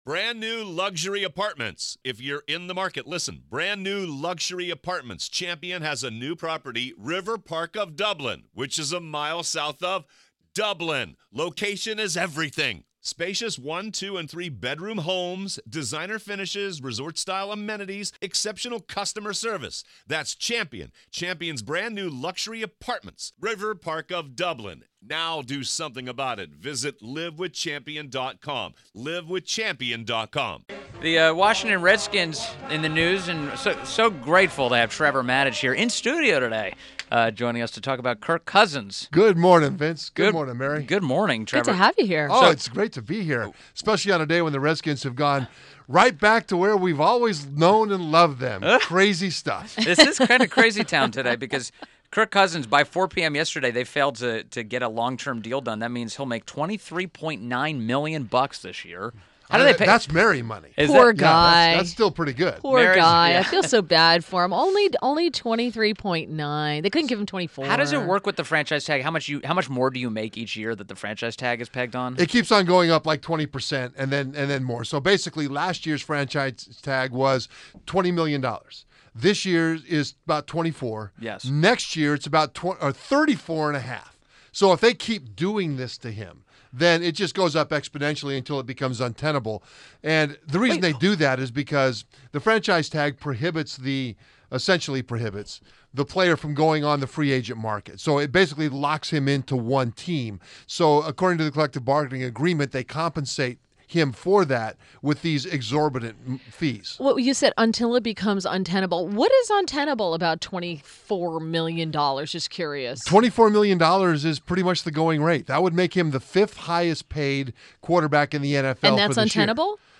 WMAL Interview - TREVOR MATICH 07.18.17